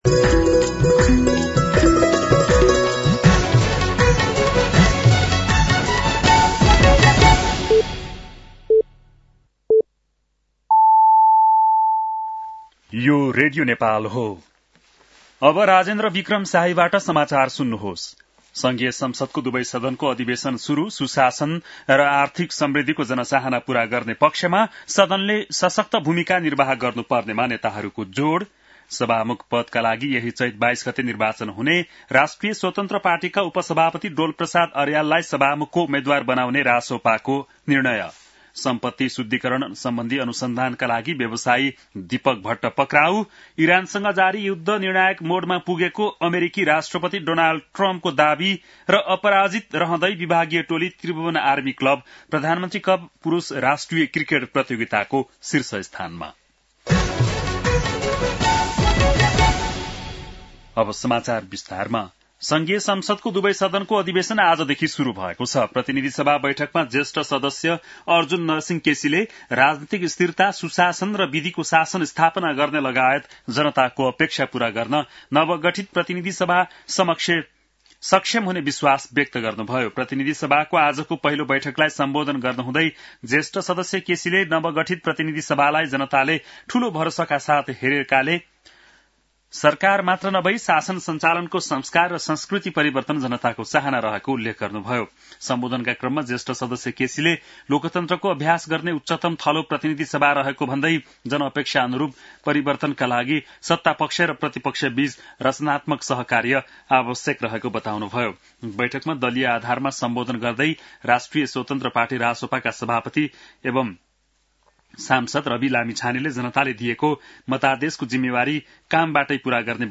बेलुकी ९ बजेको नेपाली समाचार : १९ चैत , २०८२
9-PM-Nepali-NEWS-12-19.mp3